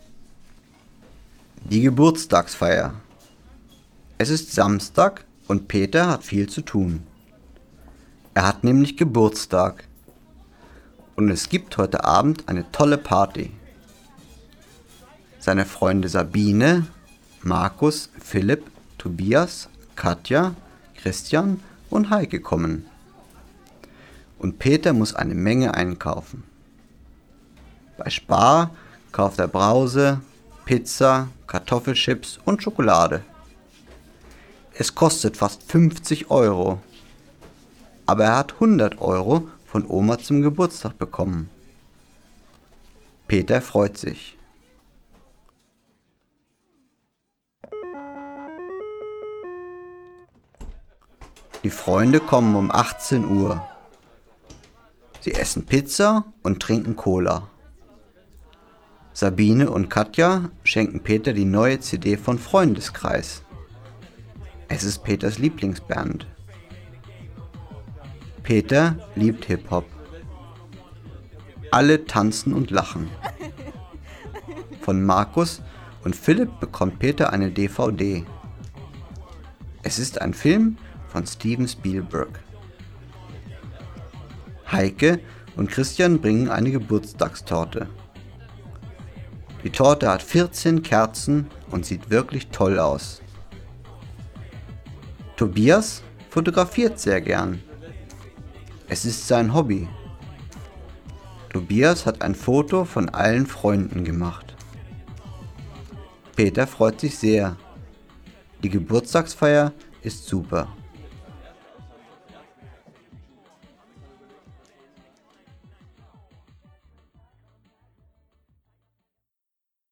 Lytteprøve